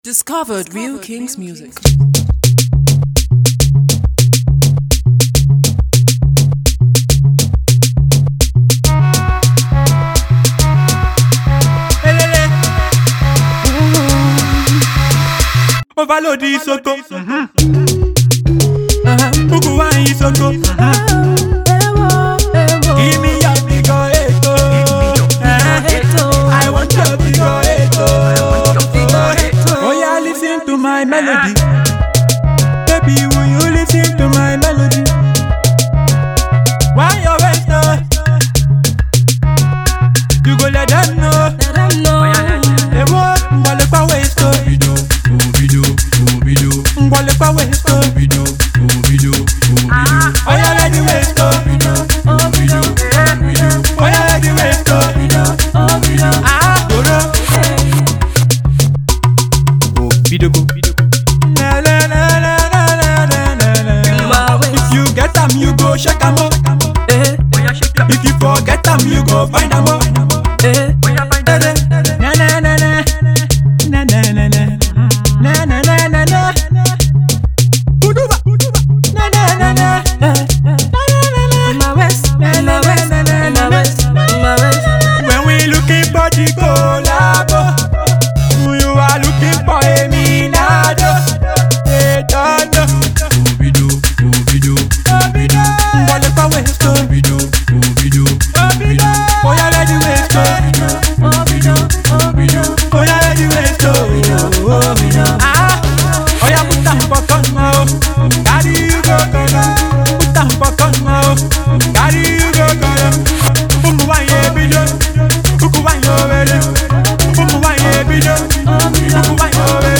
AudioPop